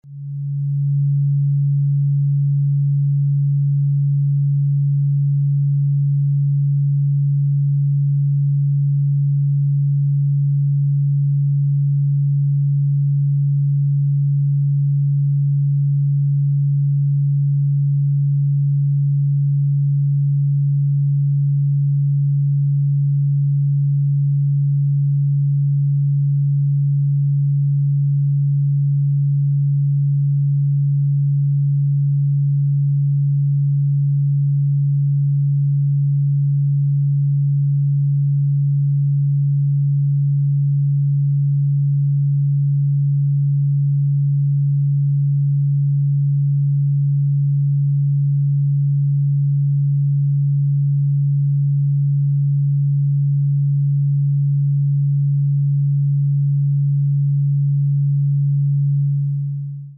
The audio frequency samples below were recorded with the app.
141.27 Hz : Frequency of Mercury
audio_141_27Hz.mp3